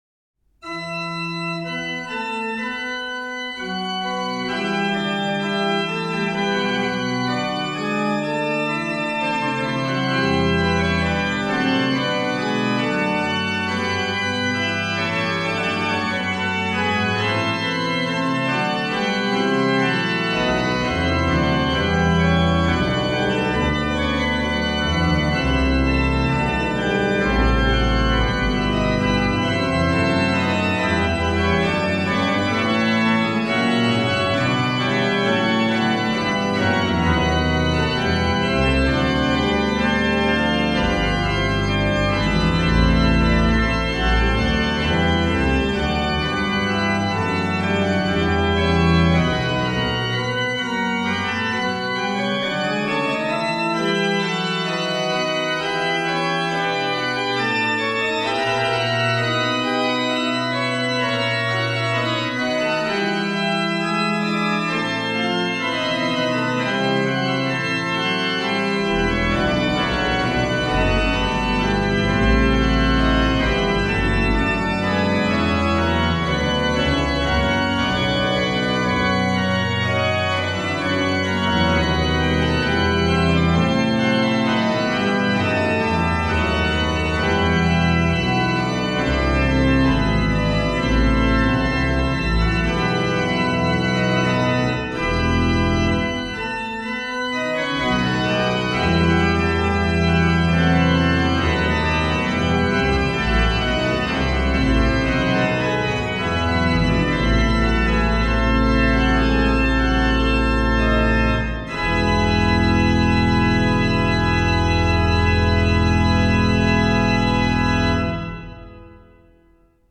Registration   OW: Pr16, Oct8, Oct4, Mix, BW/OW
BW: Ged8, Fl4, Oct2, Scharff
Ped: Oct8, Oct4, Mix, Pos16